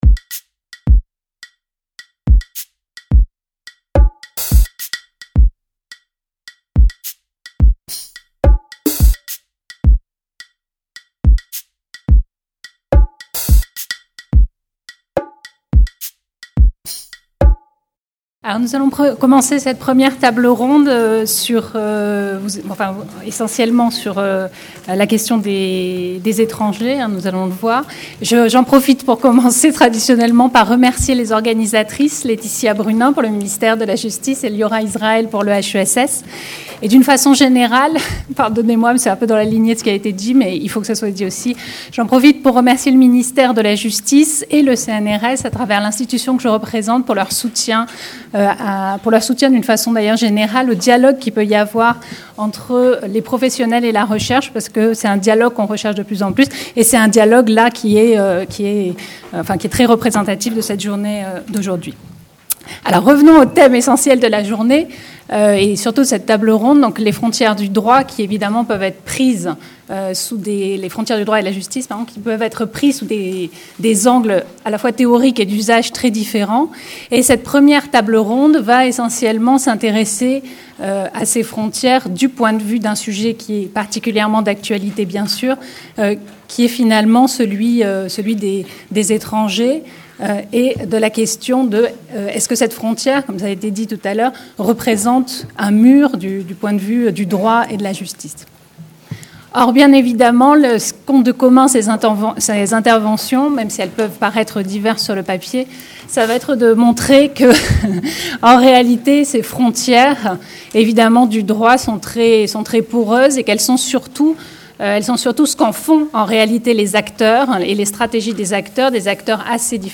Table ronde Le droit et les frontières Cette première table ronde s’interroge sur les frontières nationales au sens classique, telles qu’elles sont institutionnalisées et réitérées par le droit et la justice.